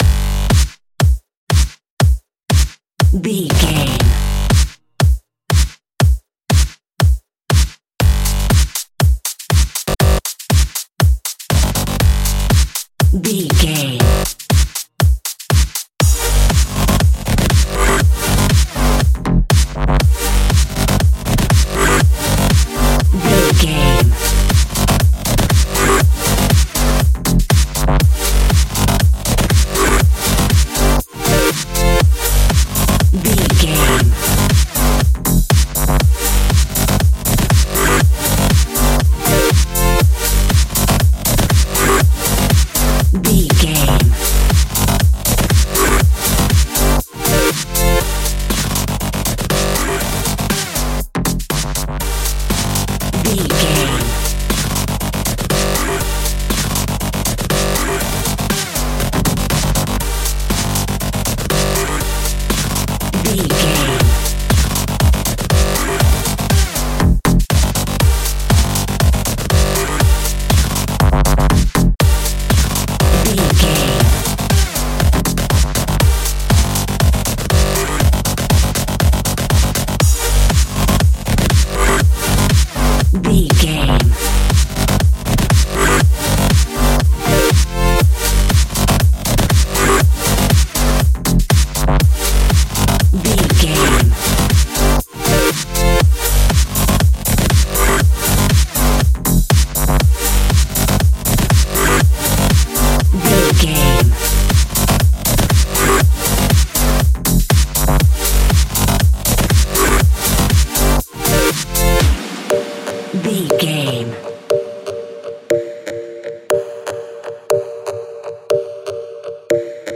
Dubstep with a Groove.
Aeolian/Minor
aggressive
dark
funky
driving
energetic
synthesiser
drums
drum machine
breakbeat
synth leads
synth bass